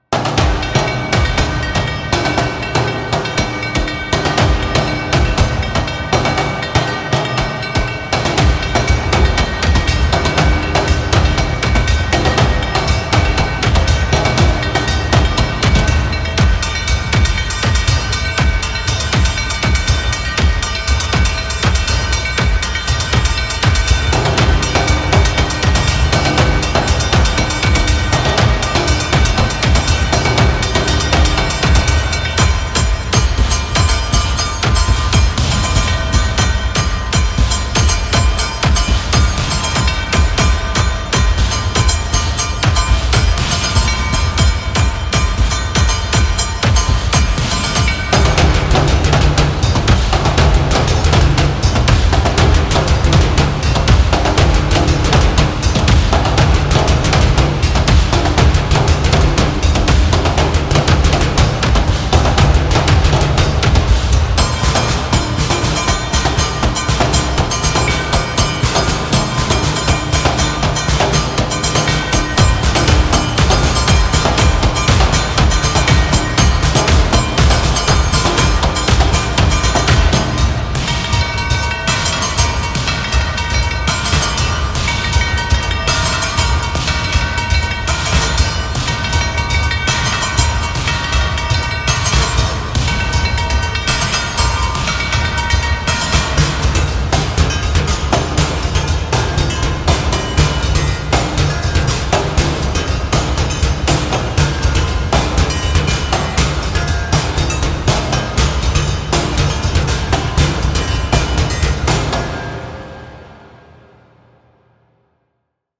激しい雰囲気のパーカッションの曲です。【BPM120】